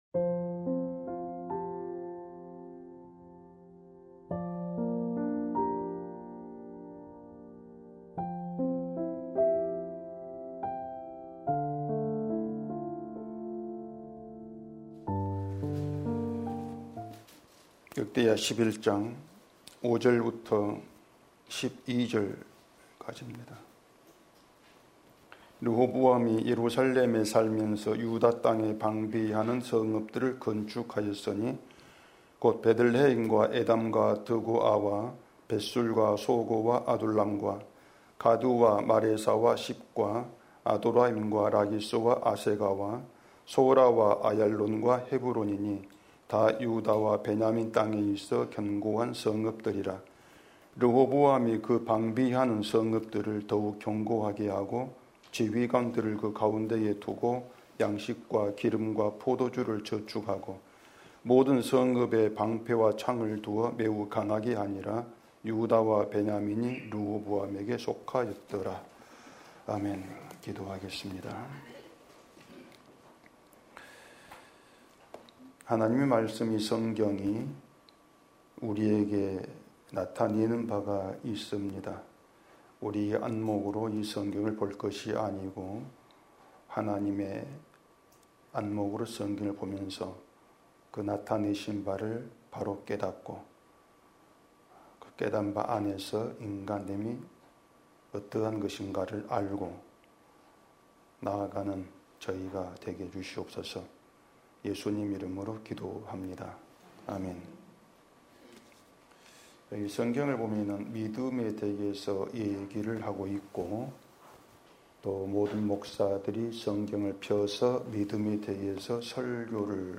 주일오전 - (47강) 르호보암의 건축